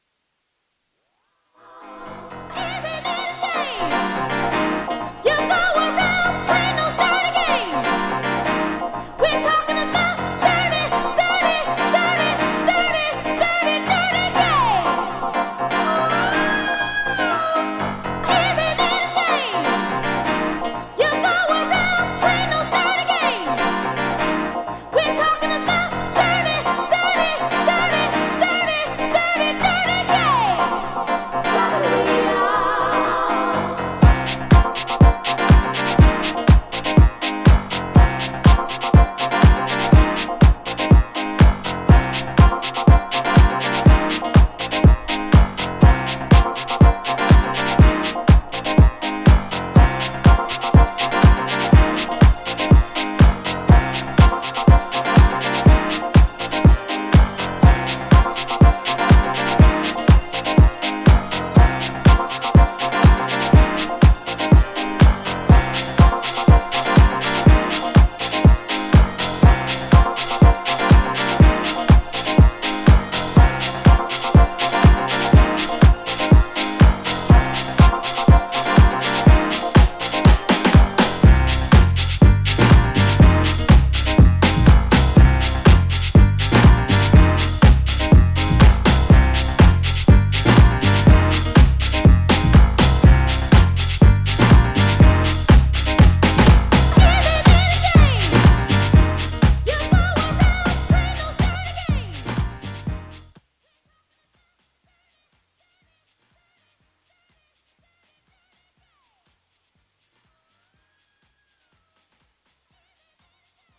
ジャンル(スタイル) CLASSIC HOUSE